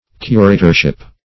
Curatorship \Cu*ra"tor*ship\, n.